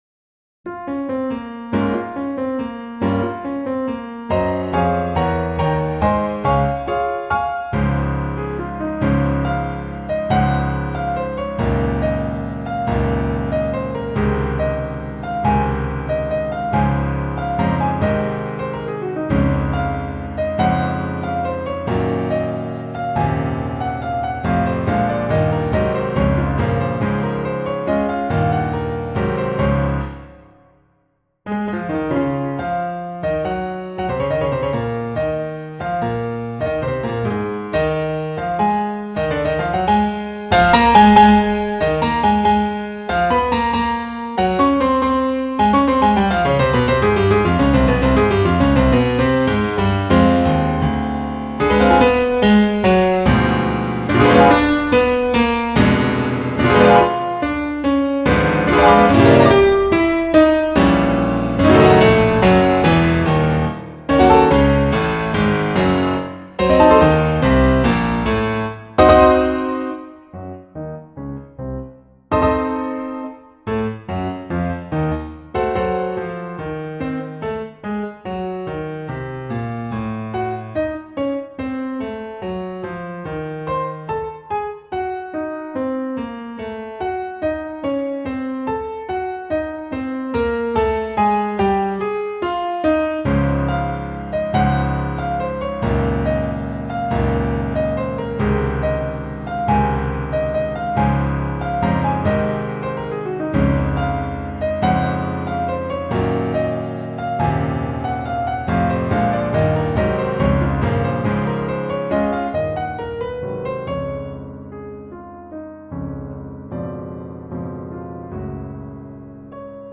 Pjotr Tsjaikovski Eerste pianokonsert Piano solo ~1985 PDF